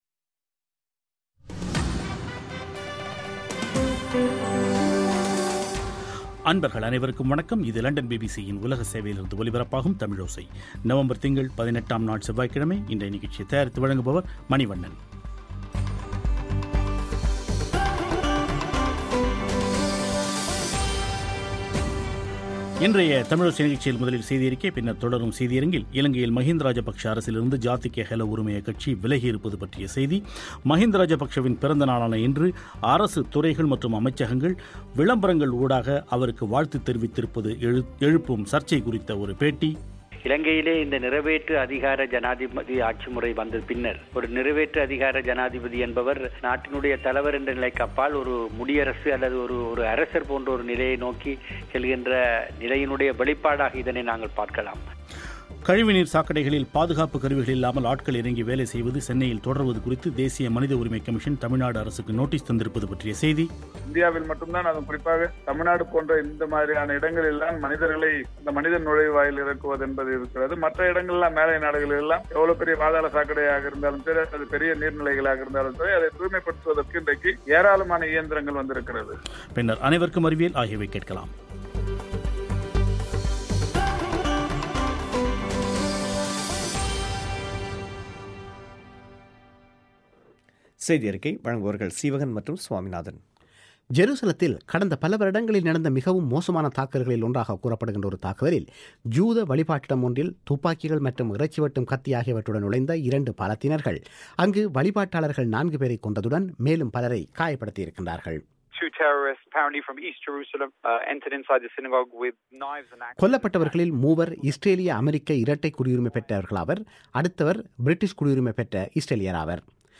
• தர்மபுரி அரசு மருத்துவமனையில் நேற்று திங்கட்கிழமை இரவு மேலும் ஐந்து பிறந்த குழந்தைகள் இறந்திருப்பது பற்றி மருத்துவமனை அதிகாரிகளுடன் ஒரு பேட்டி,